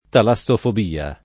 [ tala SS ofob & a ]